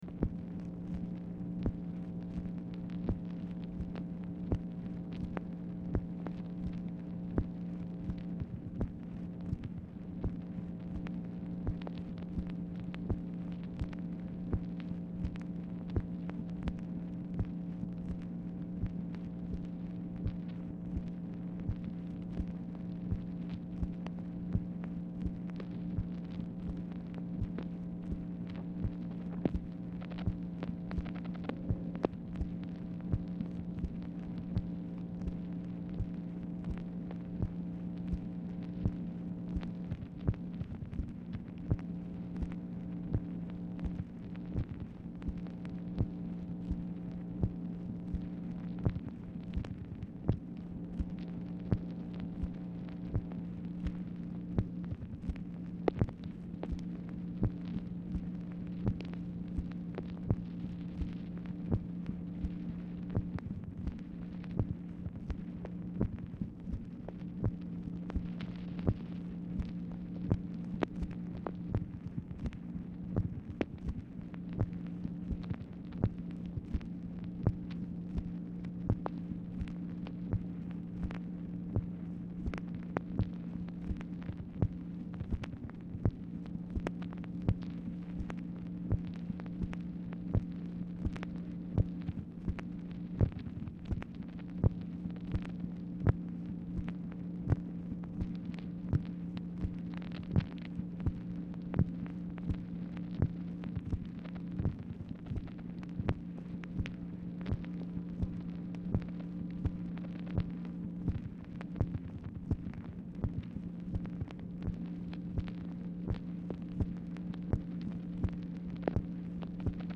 Telephone conversation # 7309, sound recording, MACHINE NOISE, 4/1/1965, time unknown | Discover LBJ
Format Dictation belt
White House Telephone Recordings and Transcripts Speaker 2 MACHINE NOISE